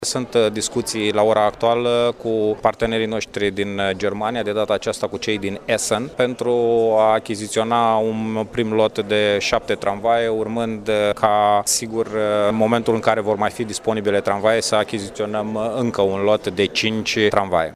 Viceprimarul Radu Botez a mai spus că se poartă negocieri cu reprezentanţii Landului ESSEN din Germania astfel încât la Iaşi să sosească două loturi de tramvaie second hand.